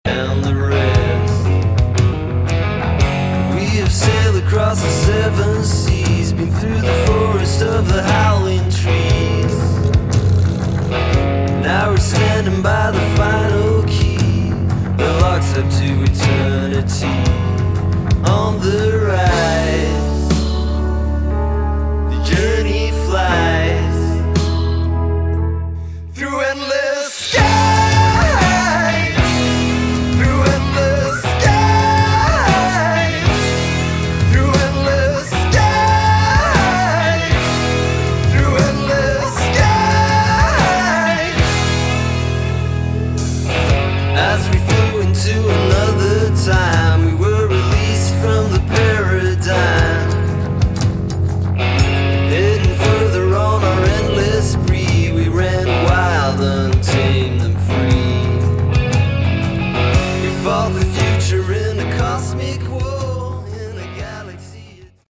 Category: Hard Rock
guitar, vocals
drums